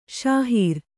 ♪ śahīr